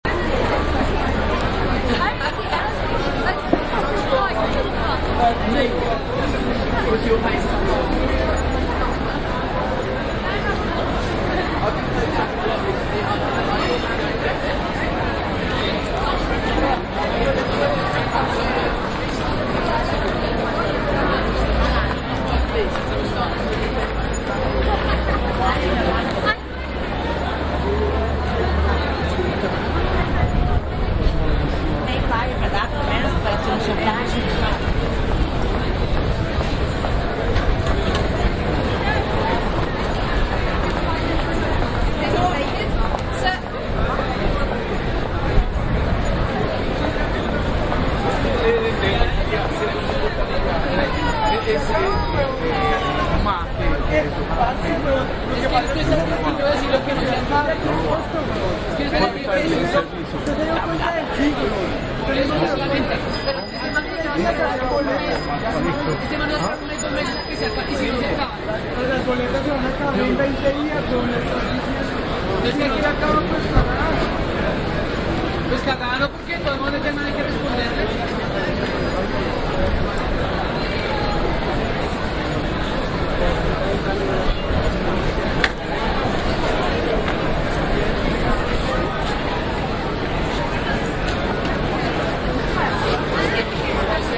伦敦市中心的双耳录音 " 摄政公园 水喷泉
描述：摄政公园 喷泉
标签： 背景声 声景 气氛 环境 伦敦 氛围 现场记录 一般噪声 大气 城市
声道立体声